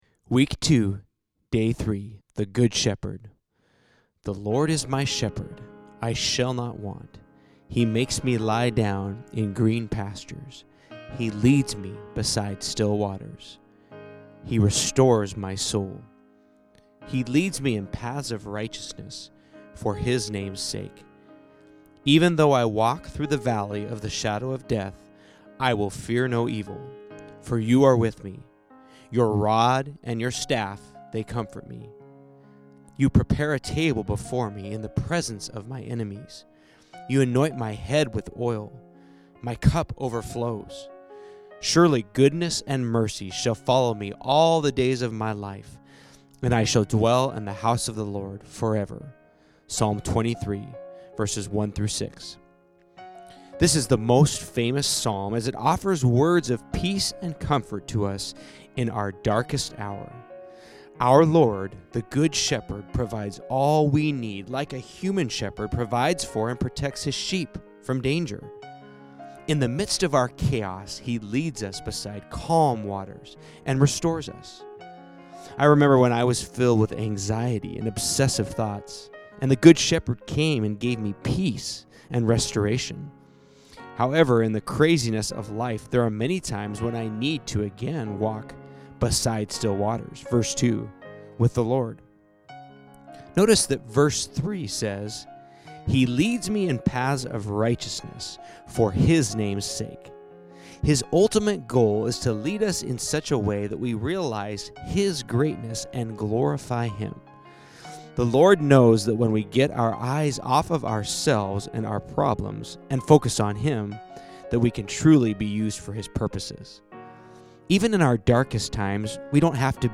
Get the Podcast (audio book version) with the piano music in the background at apple music, spotify, etc. or download at CDbaby.